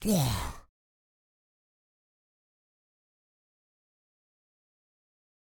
zombie_death.ogg